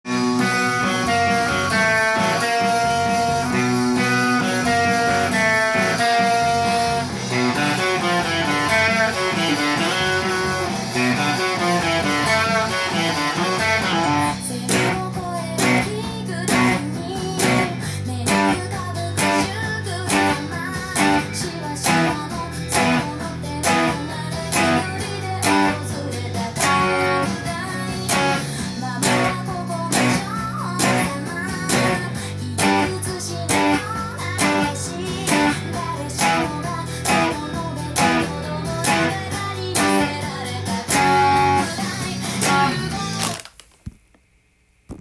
音源に合わせて譜面通り弾いてみました
2フレットをセーハしてアルペジオからスタートしています。